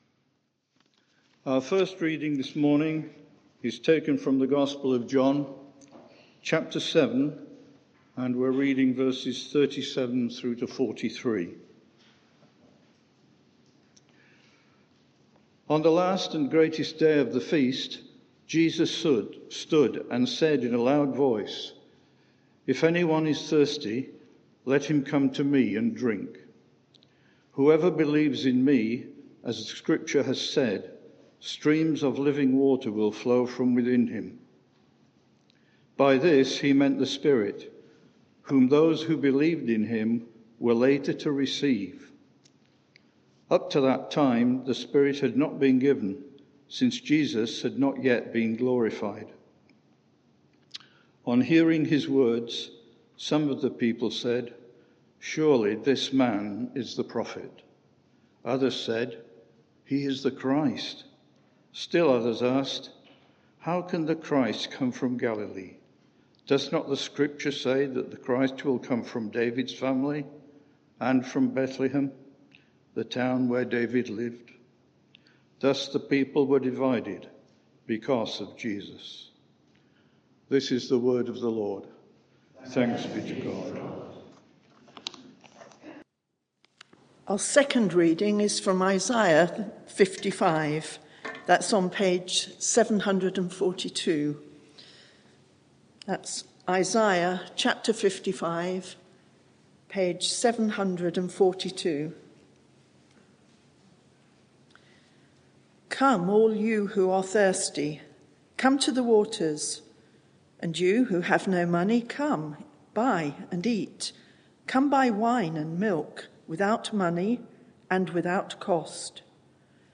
Media for 11am Service on Sun 03rd Apr 2022 11:00 Speaker
Passage: Isaiah 55 Series: The Servant King Theme: God's invitation Sermon (audio)